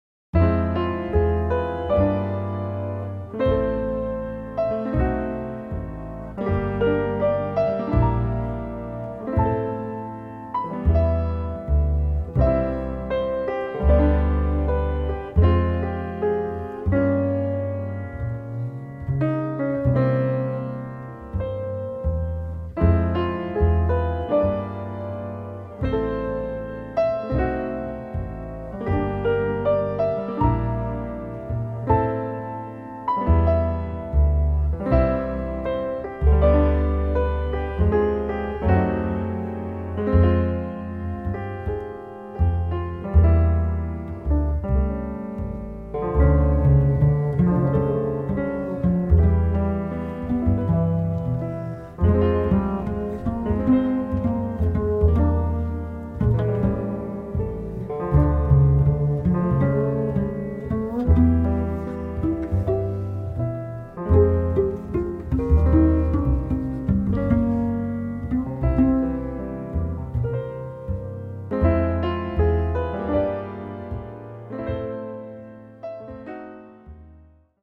The third bass solo album.
Please enjoy the calm lyric poems surrounded in gentleness.